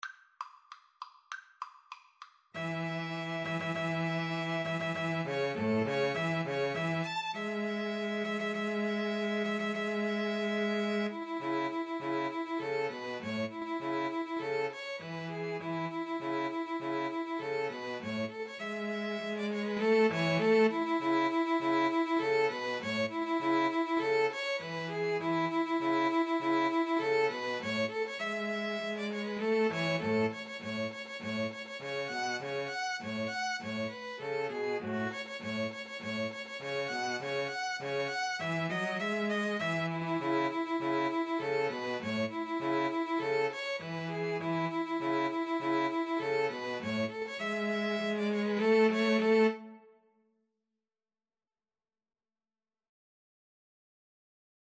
Violin 1Violin 2Cello
Presto =200 (View more music marked Presto)
Classical (View more Classical 2-Violins-Cello Music)